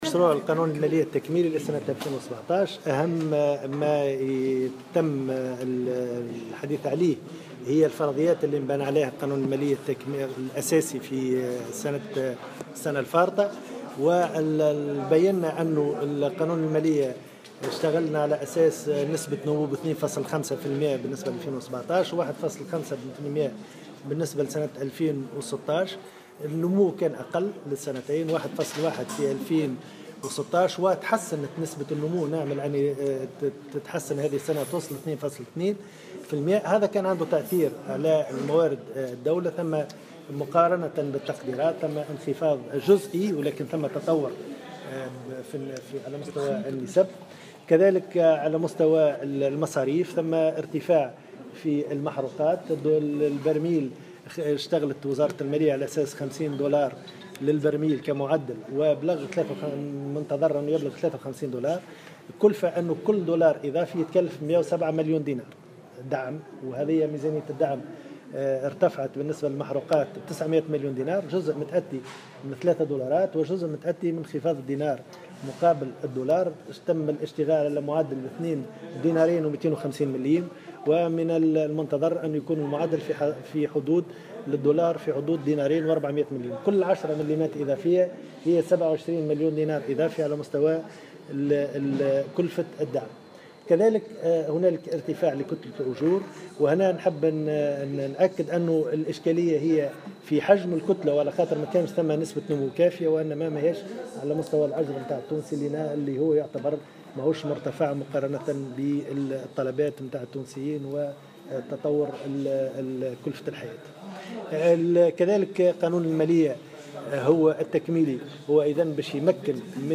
وأضاف في تصريحات صحفية على هامش لقاء في مجلس نواب الشعب أن مرد اللجوء الى هذا المشروع هو تسجيل نسبة نمو أقل مما كان متوقعا في تقديرات ميزانية 2017 وما يعنيه ذلك من تأثير على موارد الدولة مقارنة بالتقديرات على مستوى النفقات الموجهة إلى الدعم وكتلة الأجور اساسا.